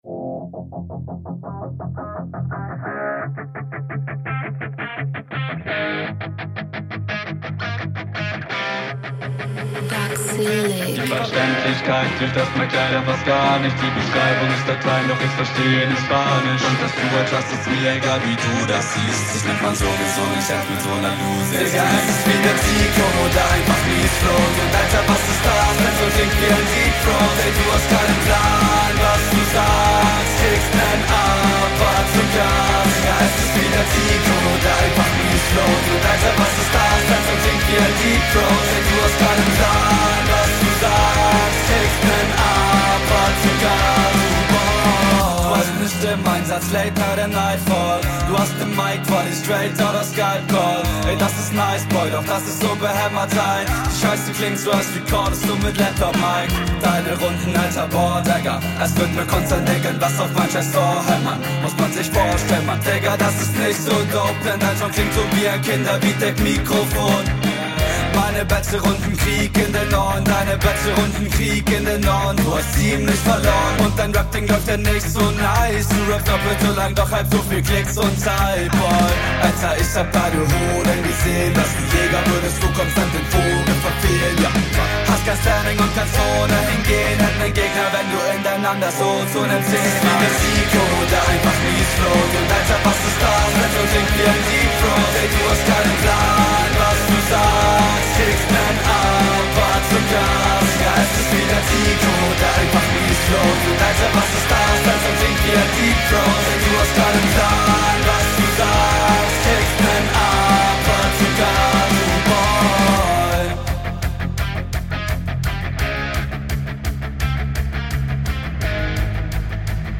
Musikalität ist top, weiter so